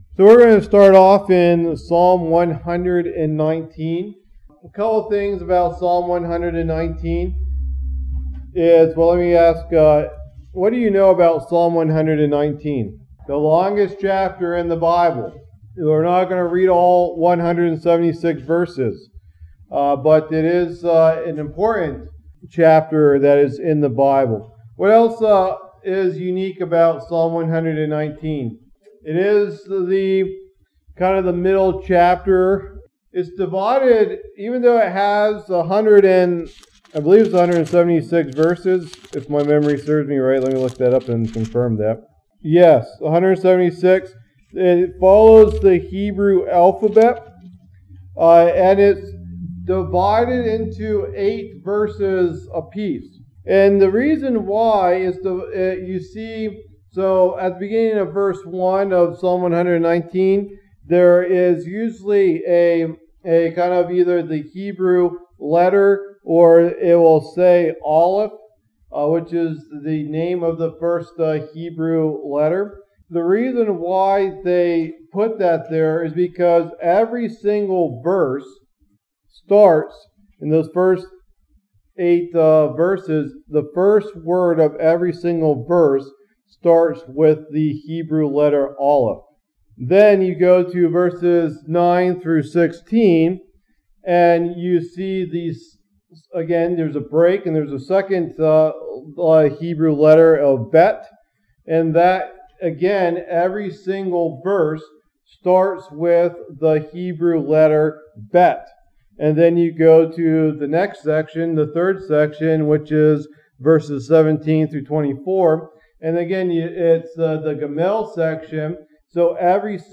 Download Download Reference Psalm 119 Sermon Notes How we Got the Bible.pdf Message #3 of the Apologetics Bible Study Apologetics Bible Study Current Teachings How did We get the Bible?